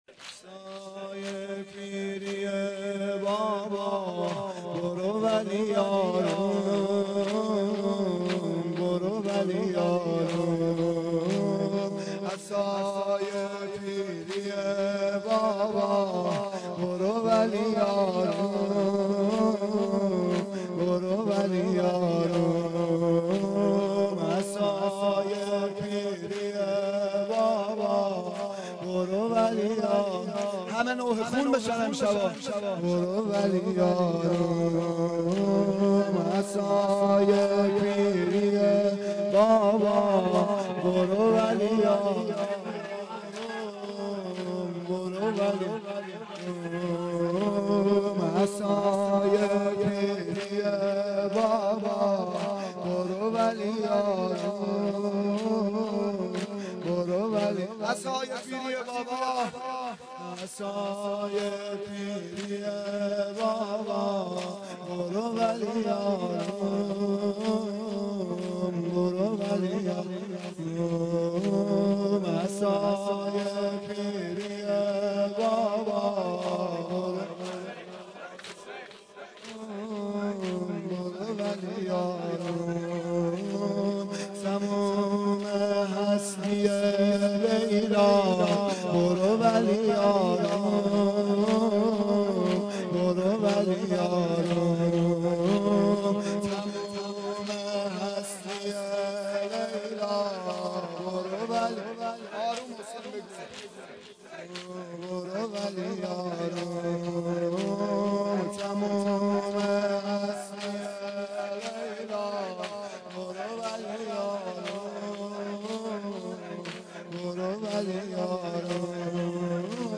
زمینه شب هشتم محرم 1391
هیئت عاشقان ثارالله کرج